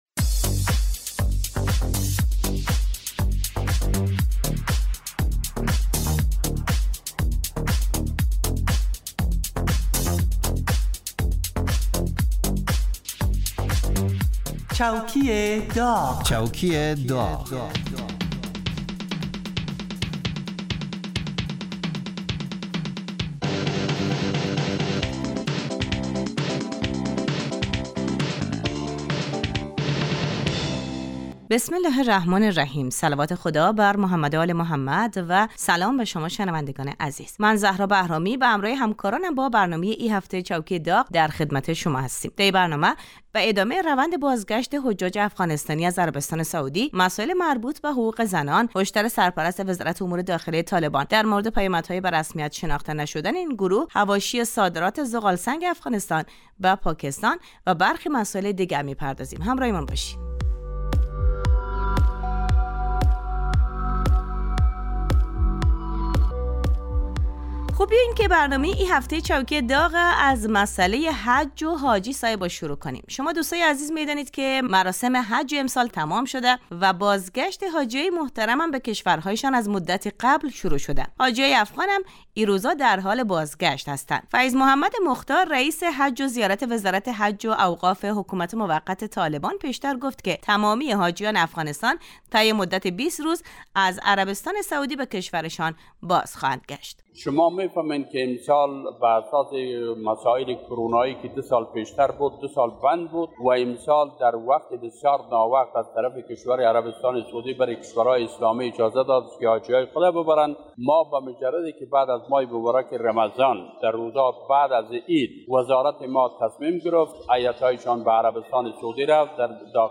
این برنامه با نیم نگاه طنز به مرور و بررسی اخبار و رویدادهای مهم مربوط به دولت حاکم در افغانستان می پردازد.